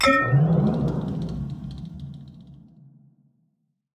Minecraft Version Minecraft Version 1.21.5 Latest Release | Latest Snapshot 1.21.5 / assets / minecraft / sounds / block / respawn_anchor / charge3.ogg Compare With Compare With Latest Release | Latest Snapshot
charge3.ogg